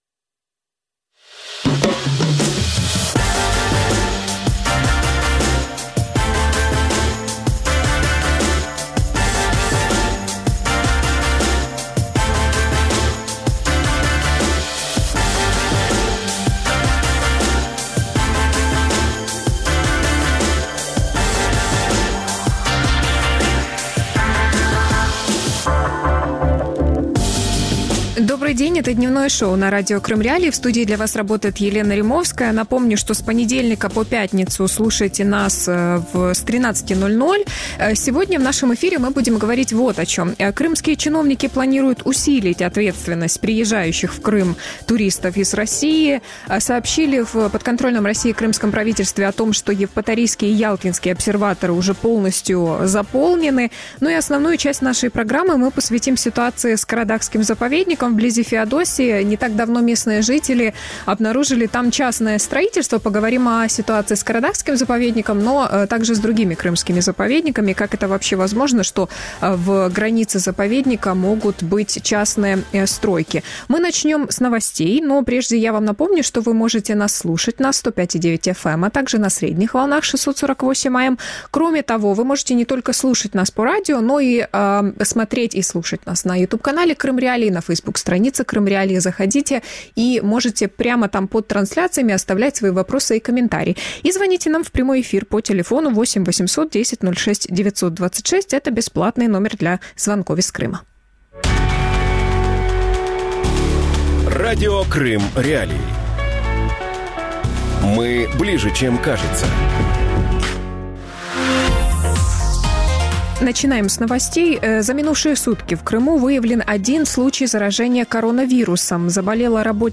Стройка на Кара-Даге и судьба крымских заповедников | Дневное ток-шоу